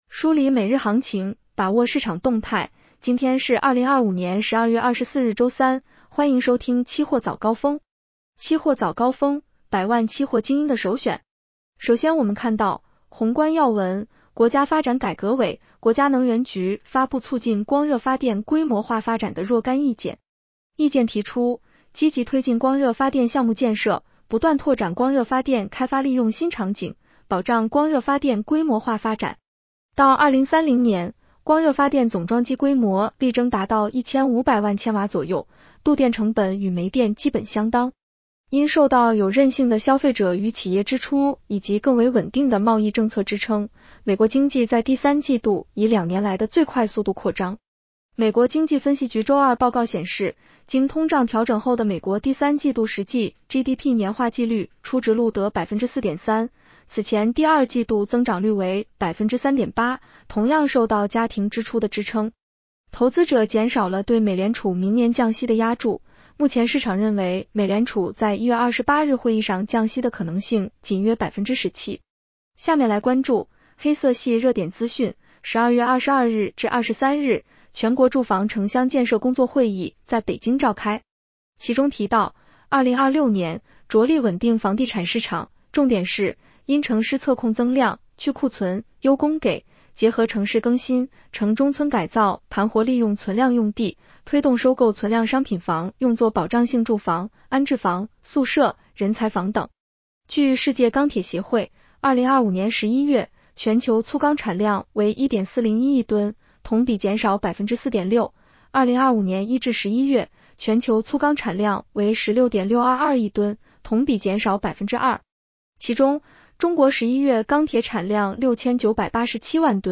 期货早高峰-音频版 女声普通话版 下载mp3 热点导读 1.